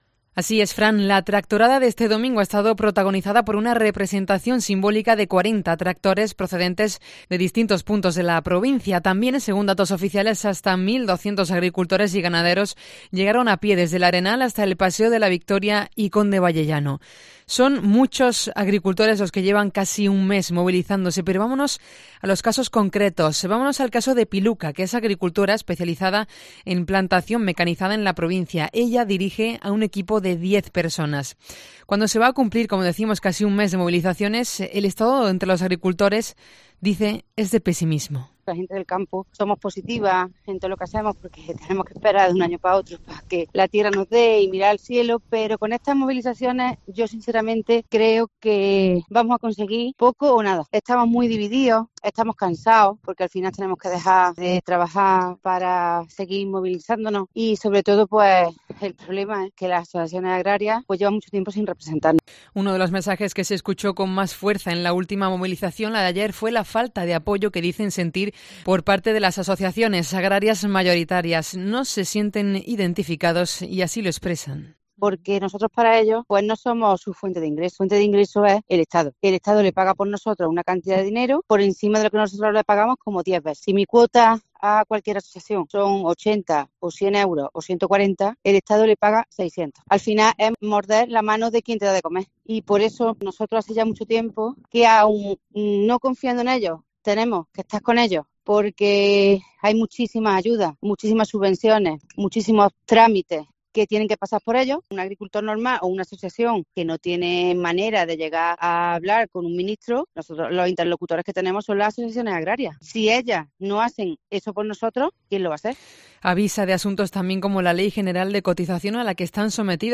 agricultora del campo cordobés